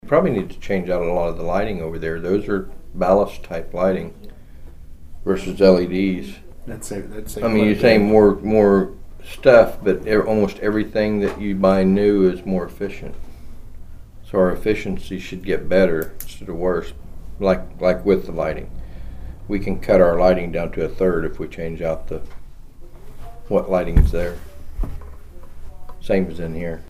The Nowata County Commissioners met on Monday morning at the Nowata County Courthouse Annex.
Commissioner Troy Friddle discussed having more efficient light fixtures.